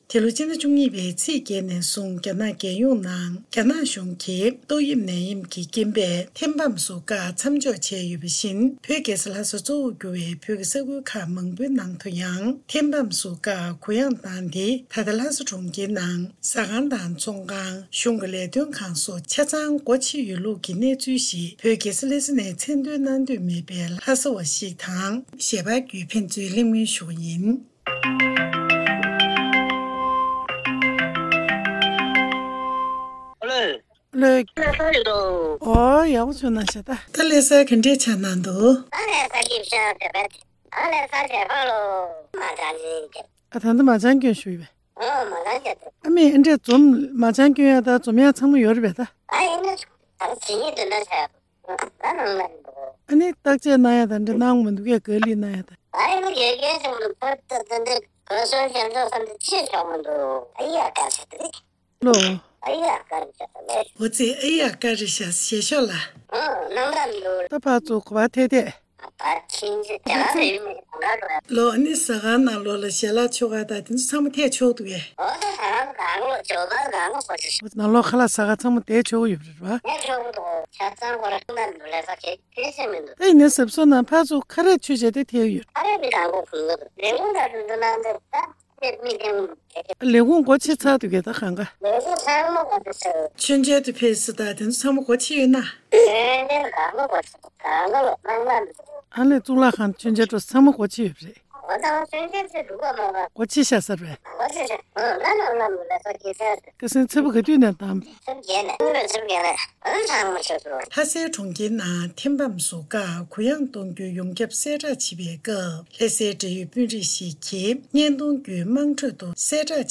བོད་ནང་ཐད་ཀར་ཞལ་པར་བརྒྱུད་གནས་འདྲི་ཞུས་པ་ཞིག་གསན་གནང་གི་རེད།